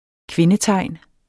Udtale [ ˈkvenə- ]